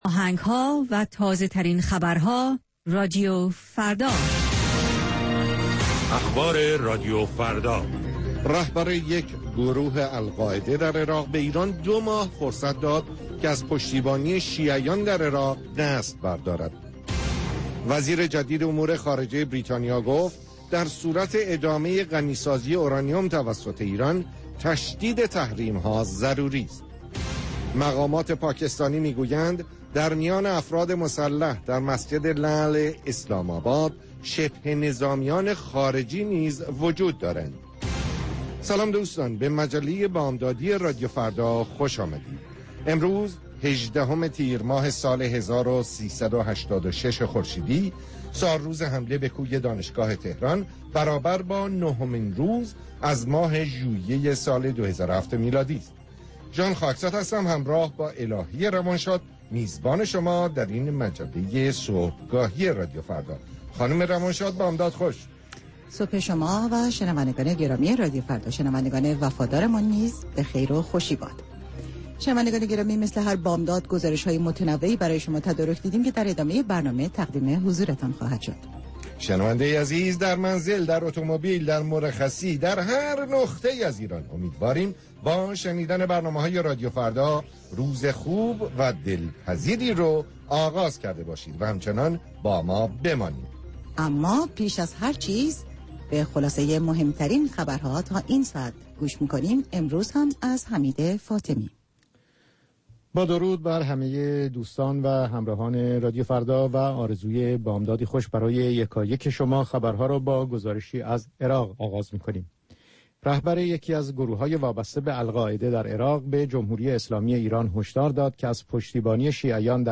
گزارشگران راديو فردا از سراسر جهان، با تازه ترين خبرها و گزارش ها، مجله ای رنگارنگ را برای شما تدارک می بينند. با مجله بامدادی راديو فردا، شما در آغاز روز خود، از آخرين رويدادها آگاه می شويد.